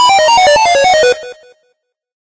8bit_die_vo_01.ogg